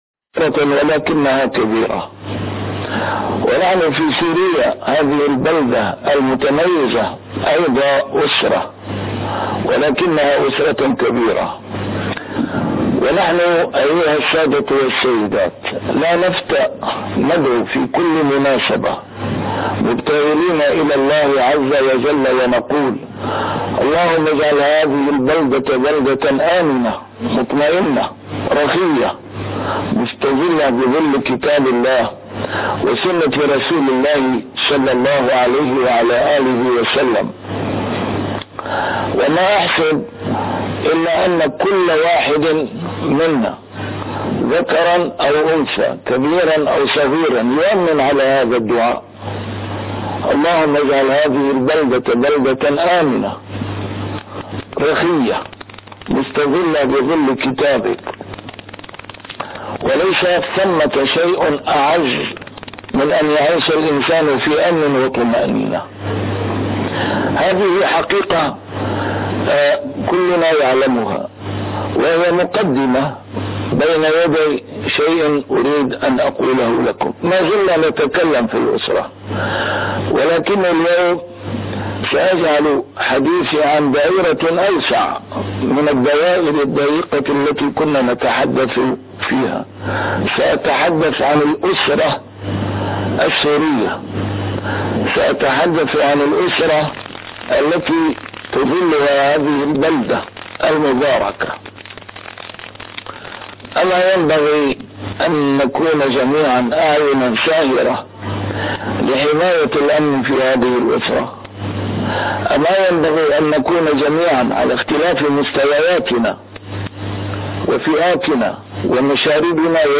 A MARTYR SCHOLAR: IMAM MUHAMMAD SAEED RAMADAN AL-BOUTI - الدروس العلمية - درسات قرآنية الجزء الثاني - الإرهاب ومكافحته